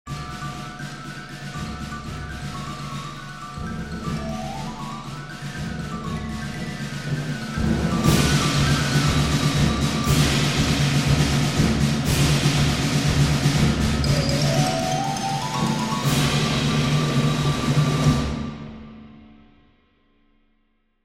01-Familias-de-PERCUSIONES-Invencion-No.1-para-Percuciones-R.Emilio.mp3